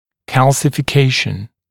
[ˌkælsɪfɪ’keɪʃn][ˌкэлсифи’кейшн]кальцификация, кальциноз